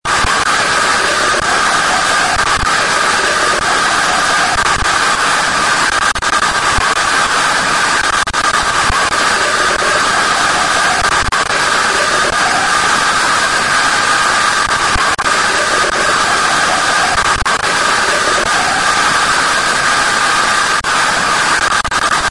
Scary Static Noise Sound Effect Download: Instant Soundboard Button